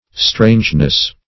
Strangeness \Strange"ness\, n.